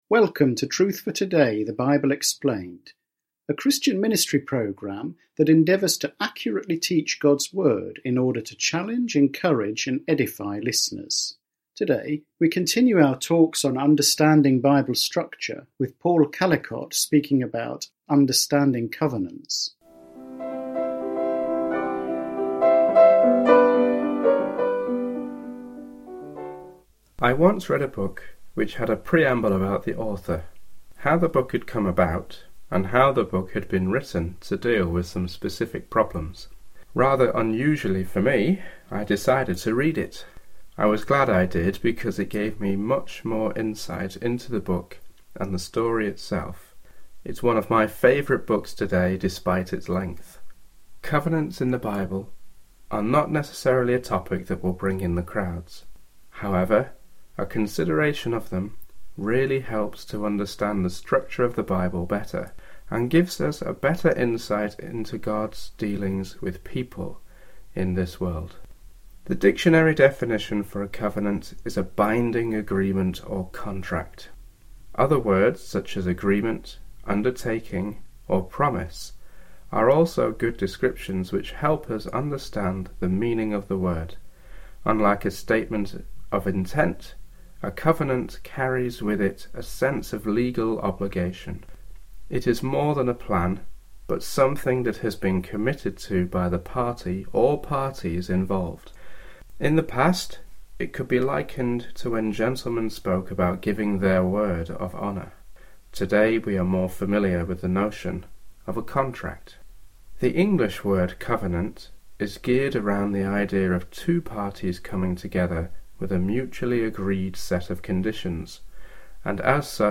Truth for Today is a weekly Bible teaching radio programme.